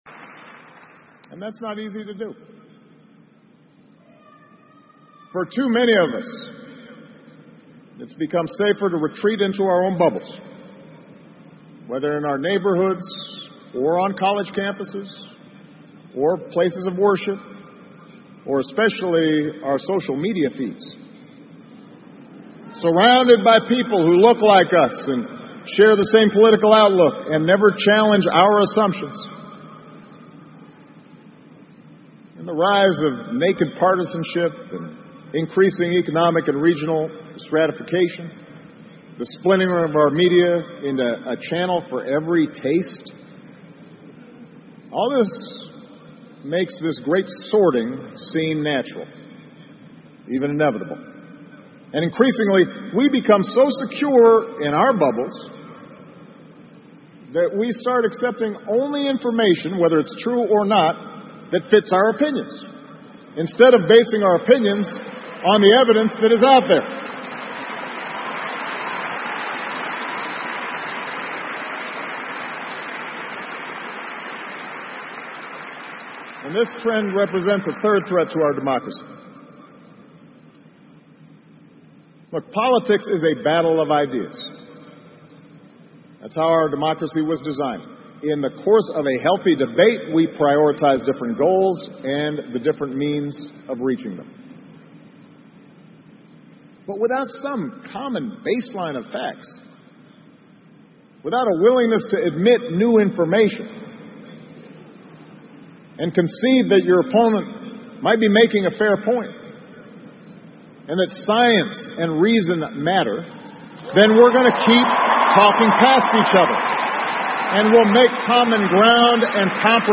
奥巴马每周电视讲话：美国总统奥巴马告别演讲(11) 听力文件下载—在线英语听力室